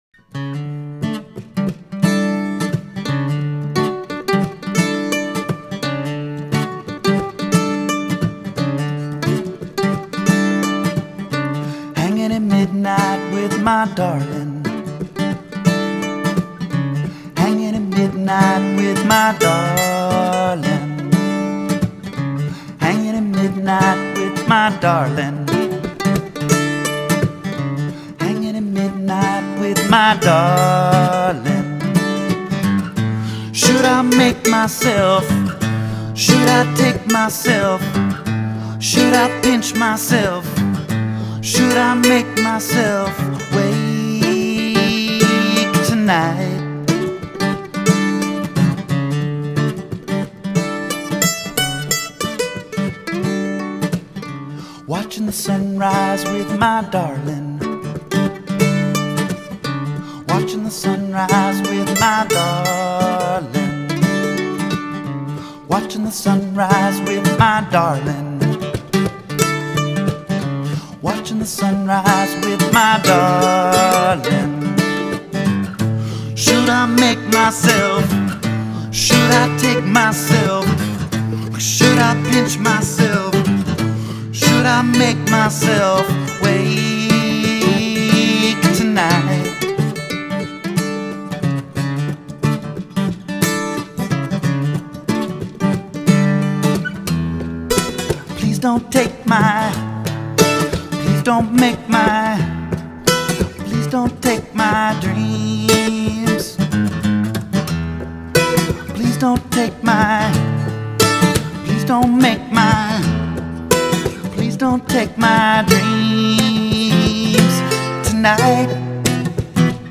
Guitar and vocals
Mandolin and fiddle